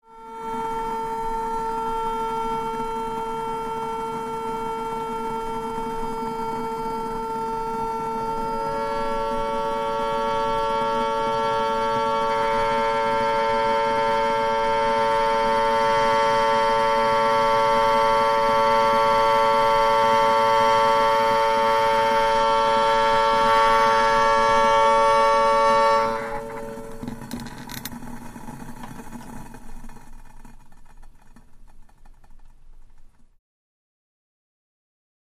Teakettle | Sneak On The Lot
Teakettle, Whistle; Pot Comes To A Boil And Strong Whistling Alarm Goes Off And Increases In Intensity Until Flame Is Turned Off And Alarm Stops